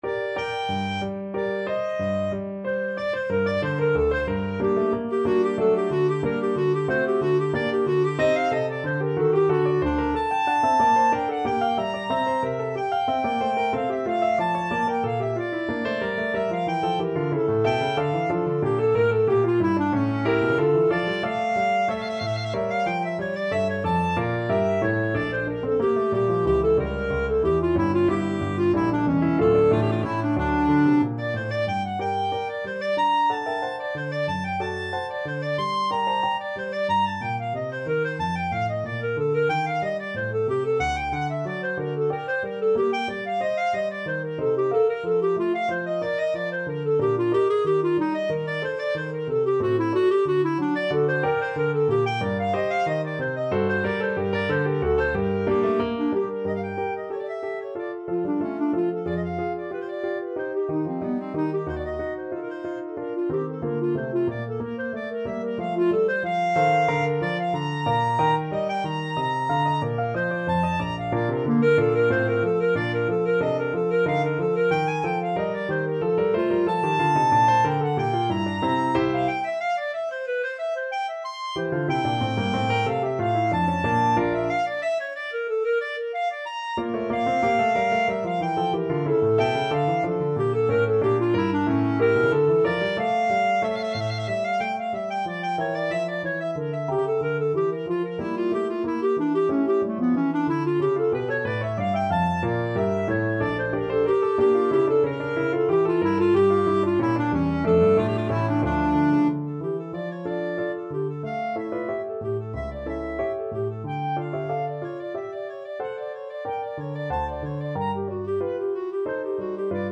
Clarinet
2/4 (View more 2/4 Music)
G minor (Sounding Pitch) A minor (Clarinet in Bb) (View more G minor Music for Clarinet )
= 92 Allegro moderato (View more music marked Allegro)
Classical (View more Classical Clarinet Music)